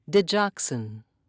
(di-jox'in)